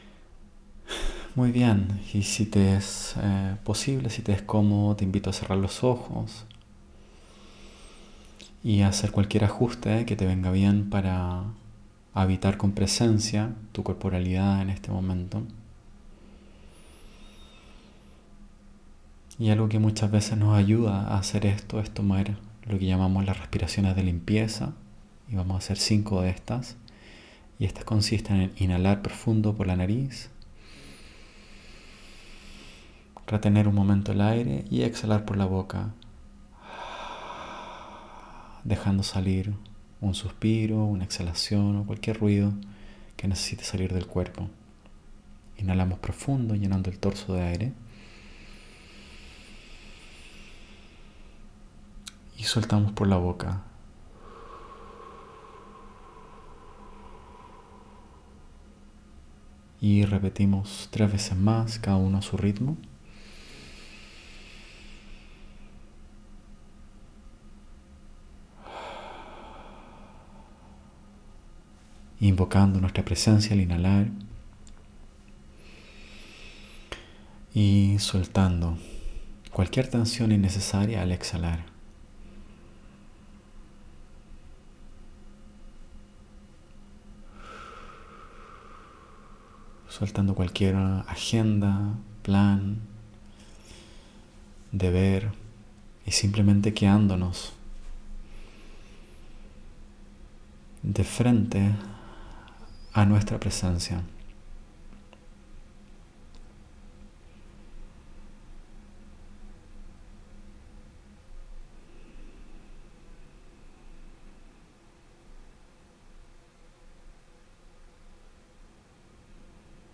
Body-Scan-30-min.mp3